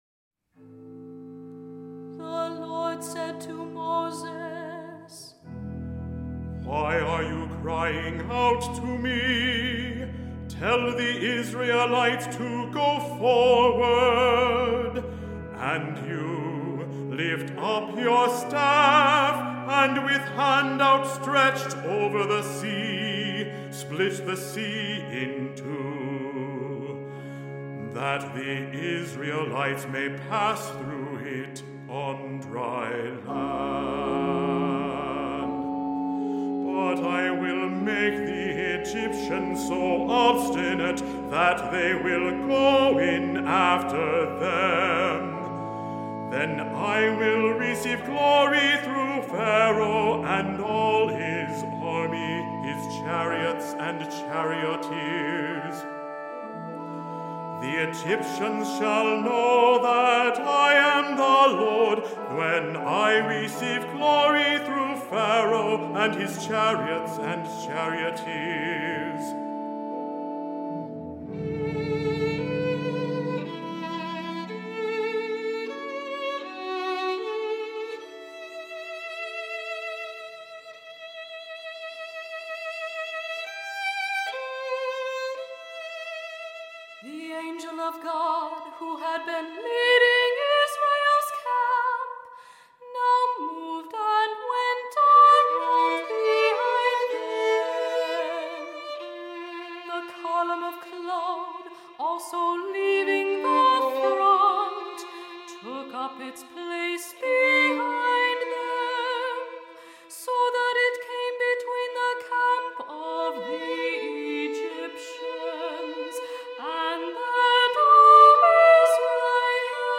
Voicing: Cantor,2 Equal Voices